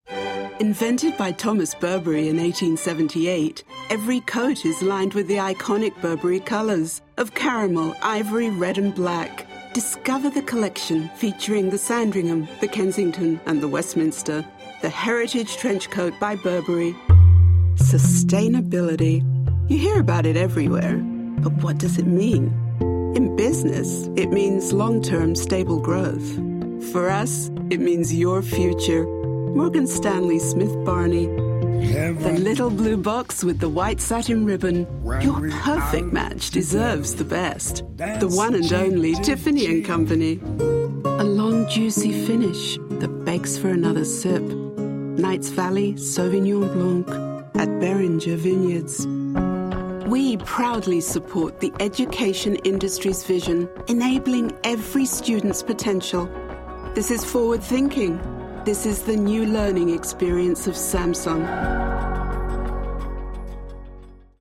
Voice Artist living in Los Angeles working in British and Global Transatlantic-Mid-Atlantic English
Sprechprobe: Werbung (Muttersprache):